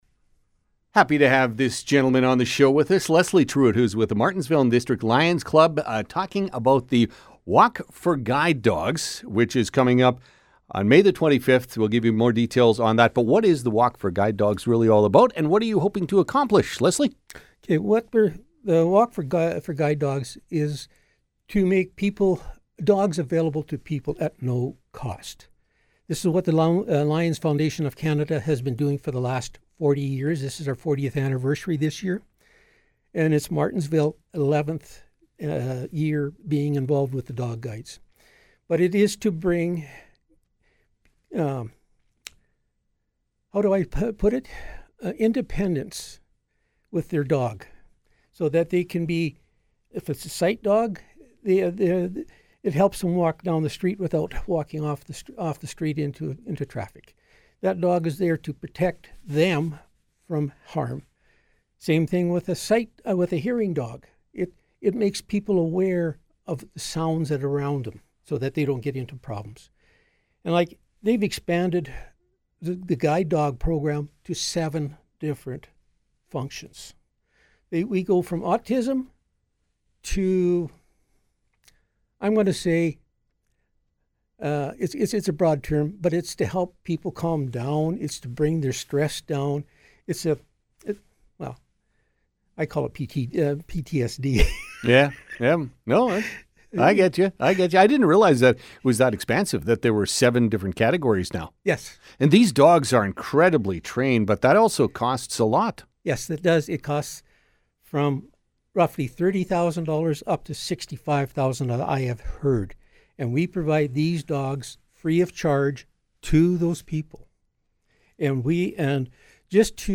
stopped by the Cool Studio to talk about the walk: